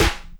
GONE, GONE Snare.wav